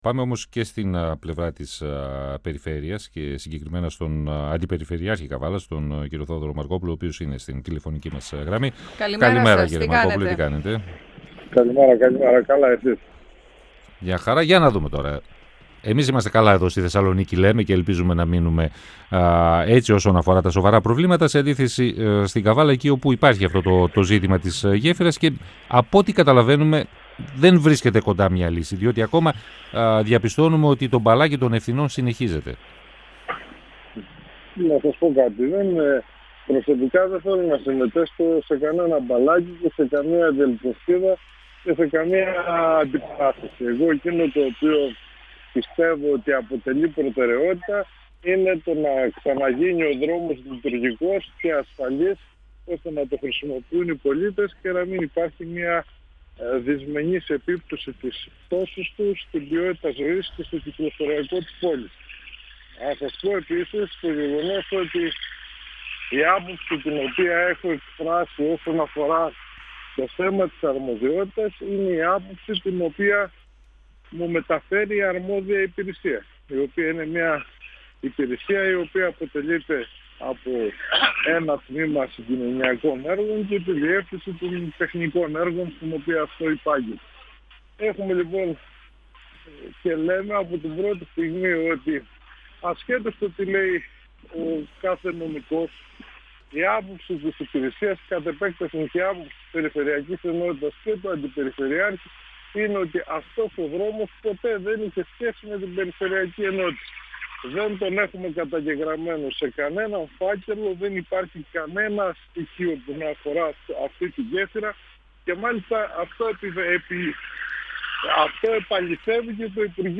Ο αντιπεριφερειάρχης Καβάλας, Θόδωρος Μαρκόπουλος, στον 102FM του Ρ.Σ.Μ. της ΕΡΤ3